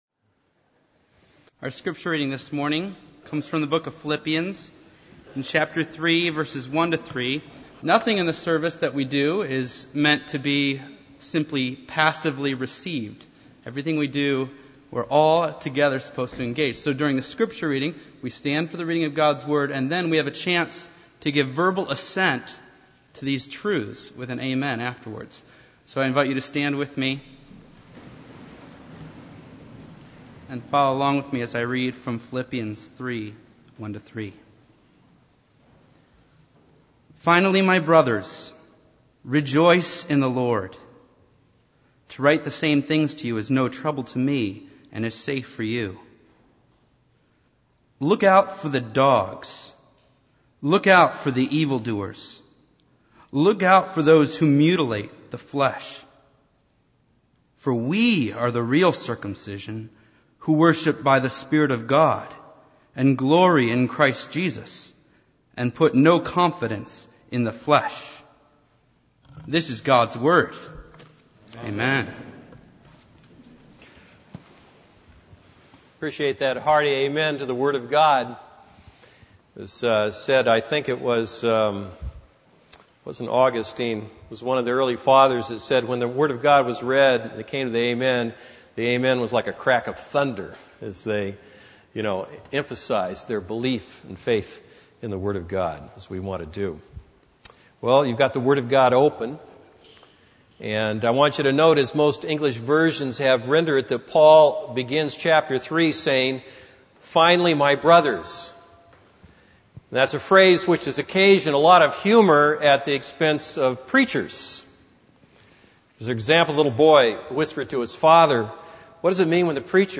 This is a sermon on Philippians 3:1-3.